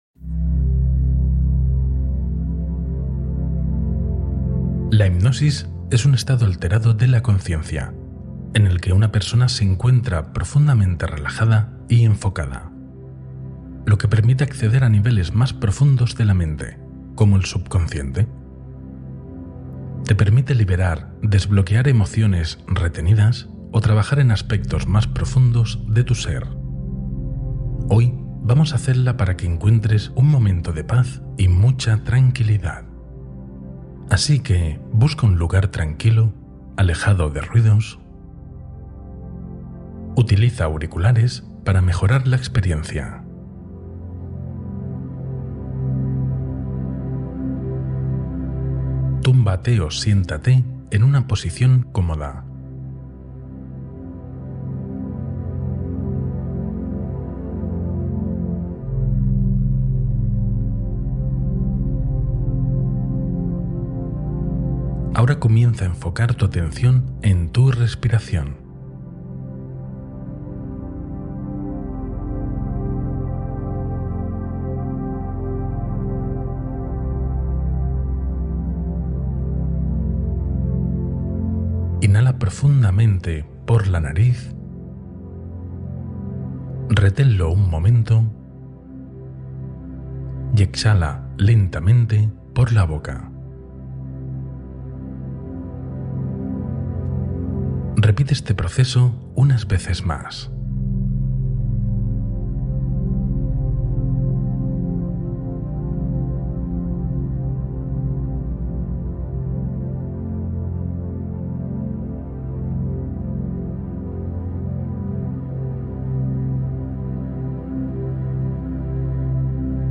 Encuentra calma y paz interior con esta hipnosis profunda